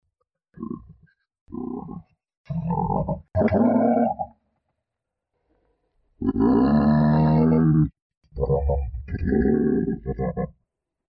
Голос жирафа